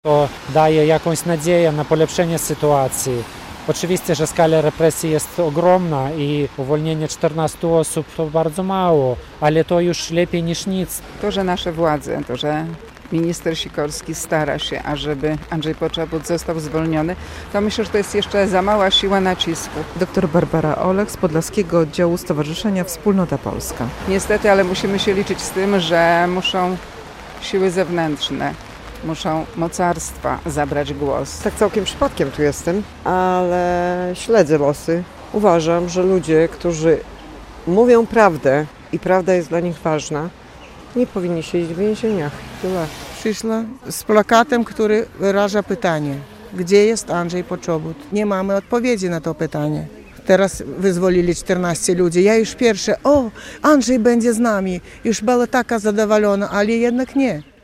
Ta akcja ma przypominać o represjach i więźniach politycznych na Białorusi. W centrum Białegostoku, obok pomnika księdza Jerzego Popiełuszki, kilkanaście osób spotkało się w środę (25.06), by wyrazić swoją solidarność między innymi z polskim dziennikarzem Andrzejem Poczobutem.